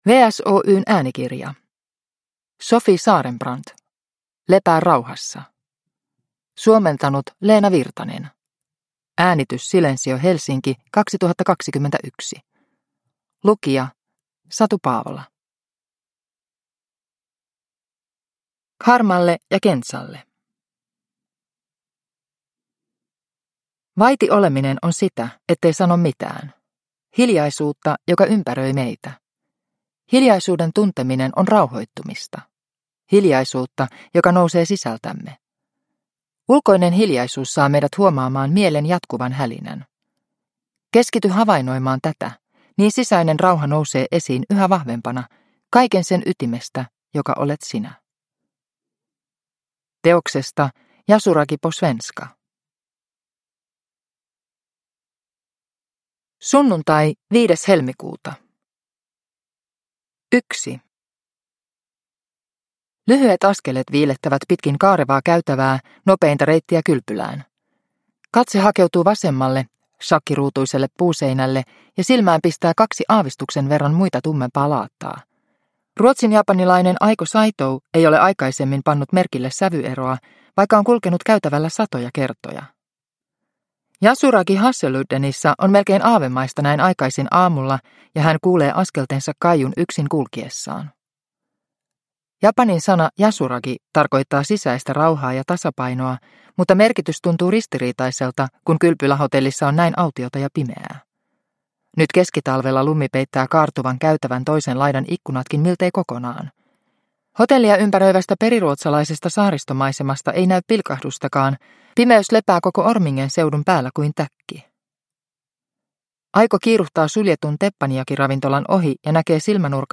Lepää rauhassa – Ljudbok – Laddas ner